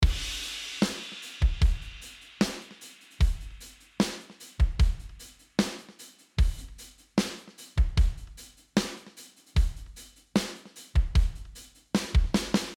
Below you can listen to some drums I have programmed using Addictive Drums 2, first without delay then 8th note delay and last dotted 8th note delay on both snare drum and overhead.
Drums With Dotted 8th Note Delay
Noice how the groove changes with the different delay times. For this particular part, the dotted 8th note delay makes it much groovier.
Drums-Dotted-8th-Note-Delay.mp3